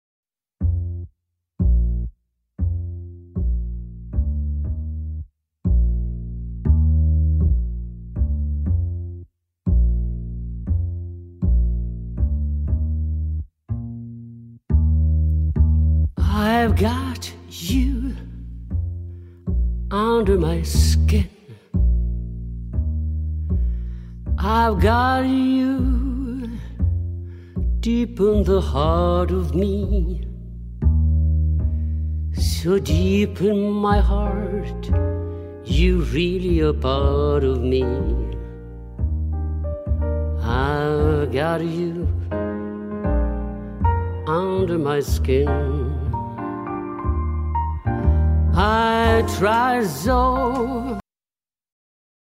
Balladen,  Jazz und Swing
mit ihrer tiefen Kontra-Alt Stimme
Orchesterbegleitung: Combo oder Big Band